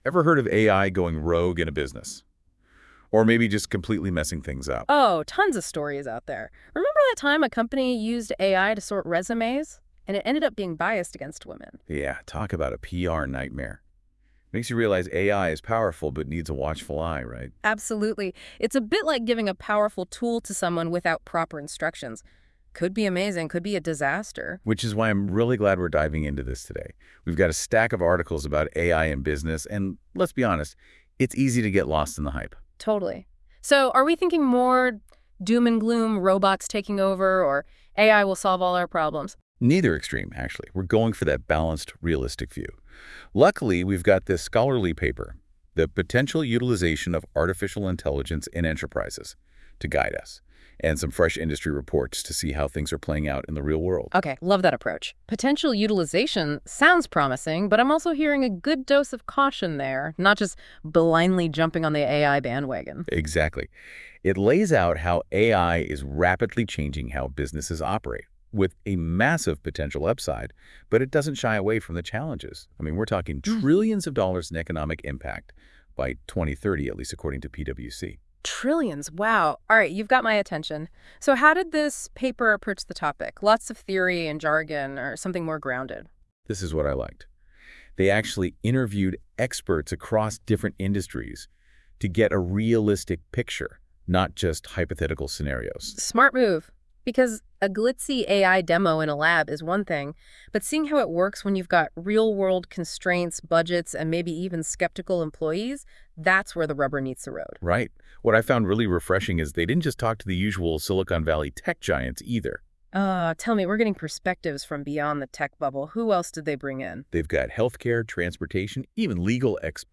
As we have been working with synthetic podcasts (aka AI-generated audio) for the last couple of months) I am always trying new ways to produce a better one.
This morning I heard of the new podcast feature of Google´s NotebookLM.
They make a two-person conversation out of it (male and female). Both of them talk about the subject.